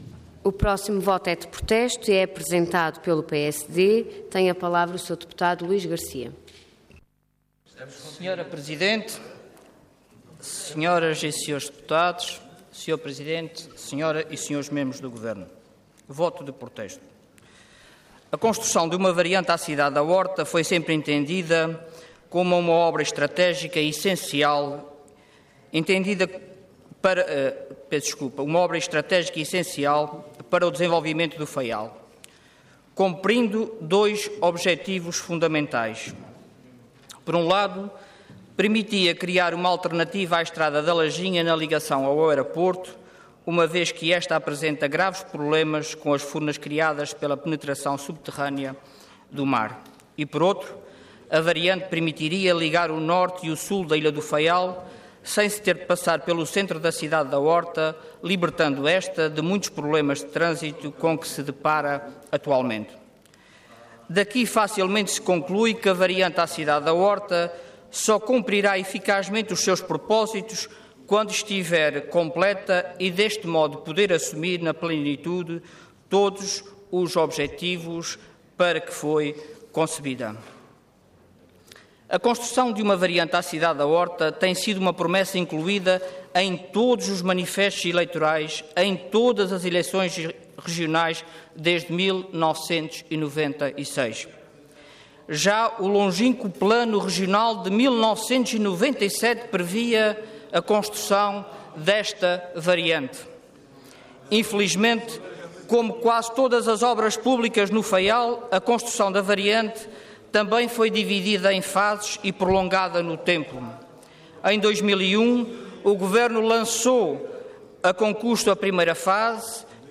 Website da Assembleia Legislativa da Região Autónoma dos Açores
Detalhe de vídeo 9 de julho de 2015 Download áudio Download vídeo Processo X Legislatura 2.ª Fase da Variante à Cidade da Horta Intervenção Voto de Protesto Orador Luís Garcia Cargo Deputado Entidade PSD